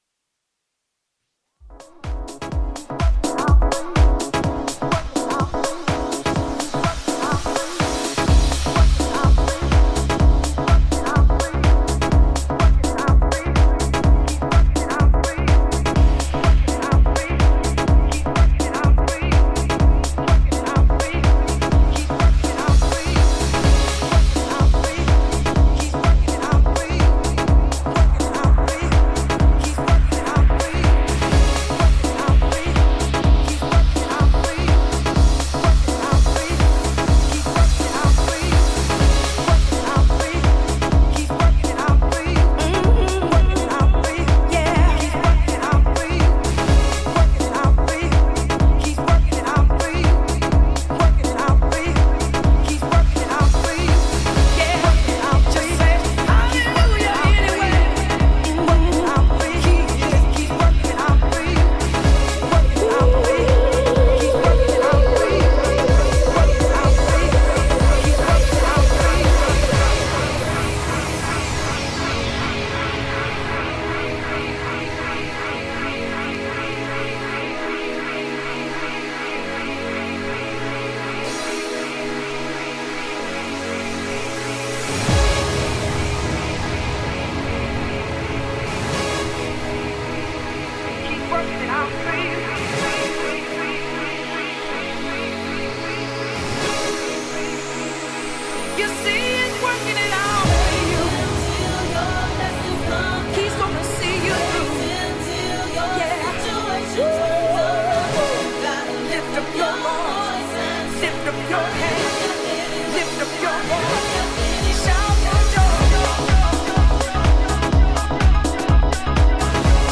ジャンル(スタイル) HOUSE / DEEP HOUSE / SOULFUL HOUSE